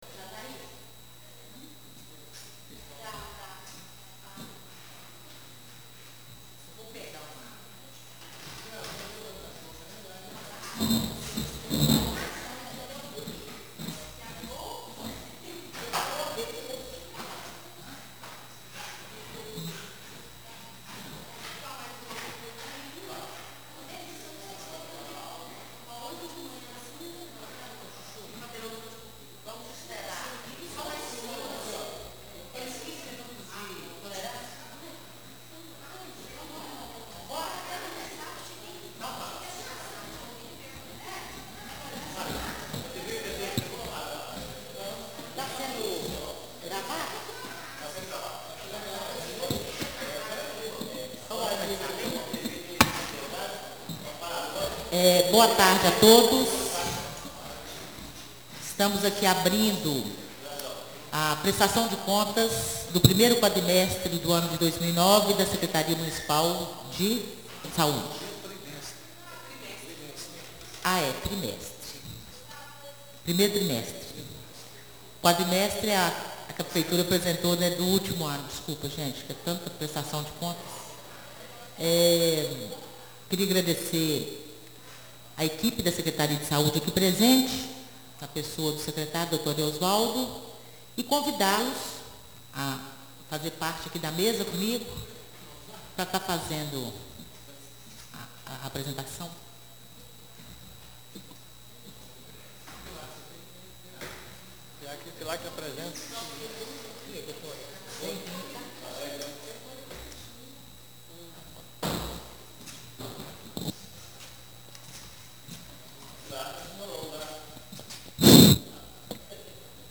Áudio: | Câmara Municipal de Ouro Preto Audiência Pública para apresentação de relatório detalhado das ações da Secretaria de Saúde 1° quadrimestre (Prestação de Contas janeiro, fevereiro, março e abril) Reunião Compartilhar: Fechar